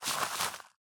Minecraft Version Minecraft Version 1.21.5 Latest Release | Latest Snapshot 1.21.5 / assets / minecraft / sounds / block / composter / ready3.ogg Compare With Compare With Latest Release | Latest Snapshot